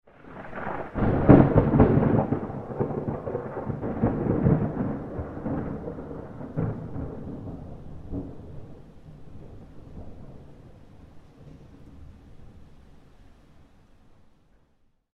Thunder Clap Sound Effect No Rain
Description: Thunder clap sound effect no rain. Powerful thunder strike sound effect recorded close to the listener for maximum impact.
Thunder-clap-sound-effect-no-rain.mp3